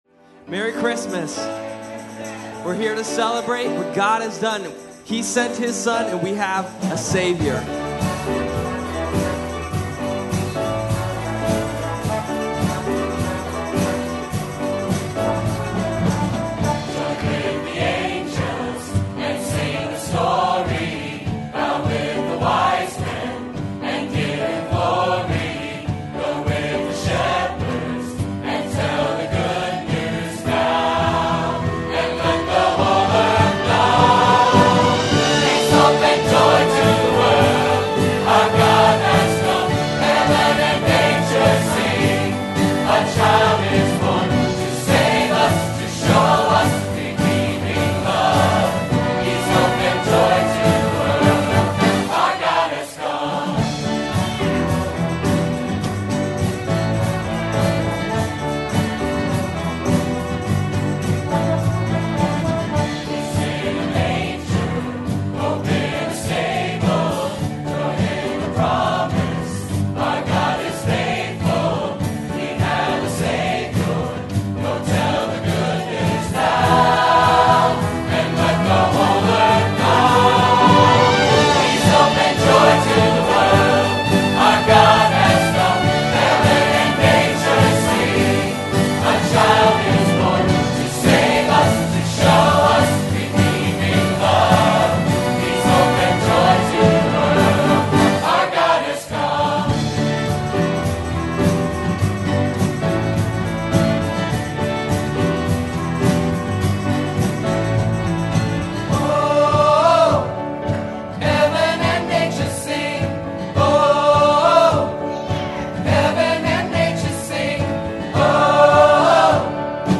Peace, Hope & Joy - 2015 Christmas Musical
Peace, Hope & Joy is a festive Christmas musical celebrating the birth of our Lord and Savior Jesus Christ through powerful Christmas praise and worship songs.